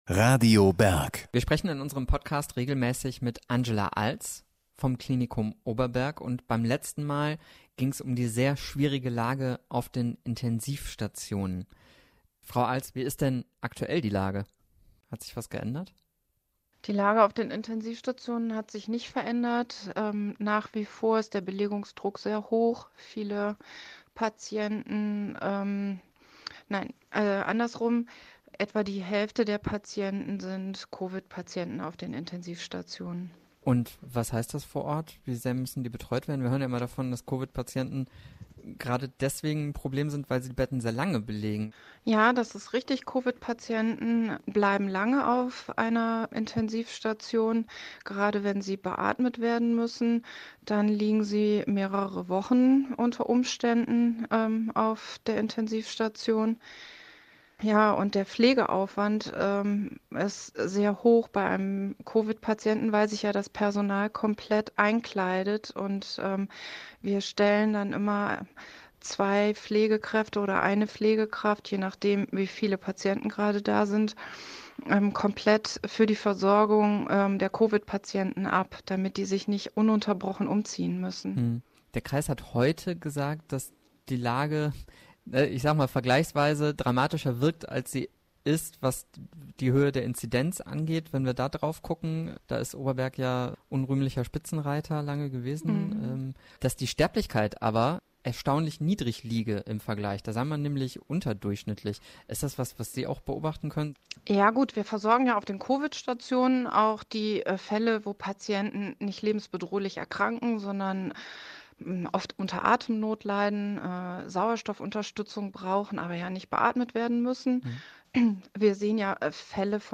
Interviews im Herbst / Winter 2021 - Welle 4